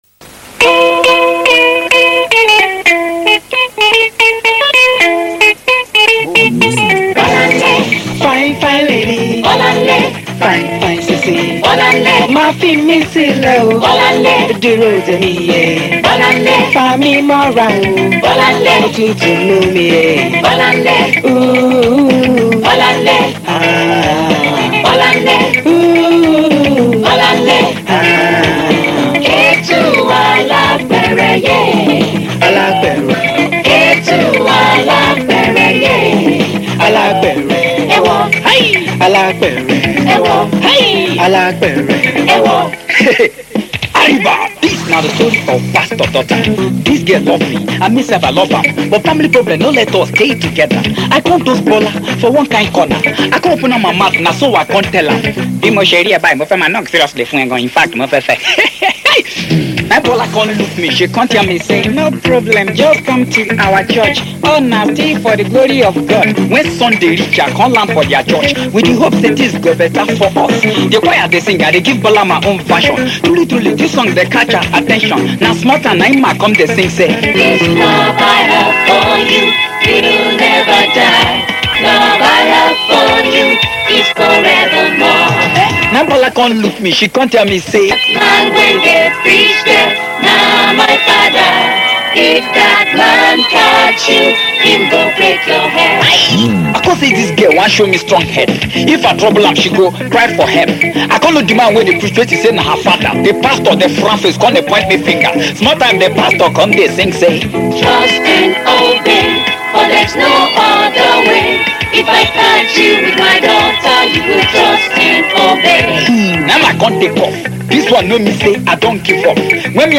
Home » Ragae
Wonderful Reggae Music